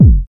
Index of /90_sSampleCDs/USB Soundscan vol.02 - Underground Hip Hop [AKAI] 1CD/Partition C/06-89MPC3KIT